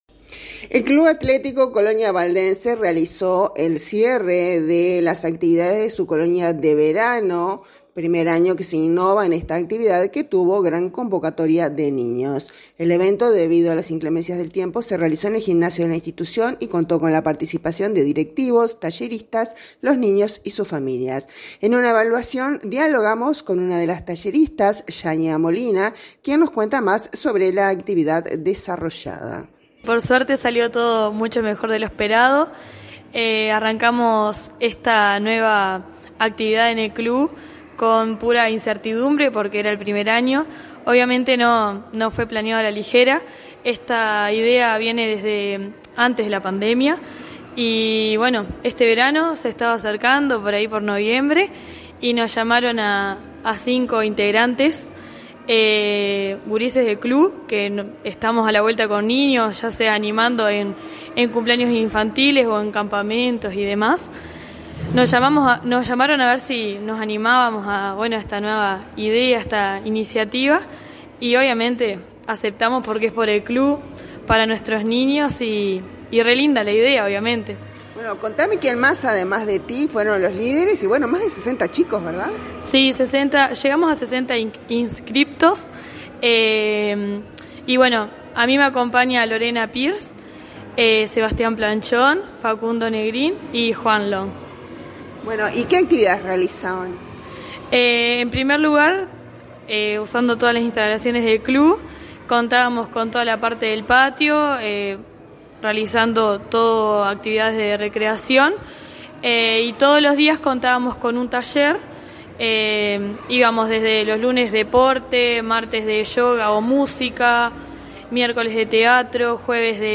dialogamos con una de las Talleristas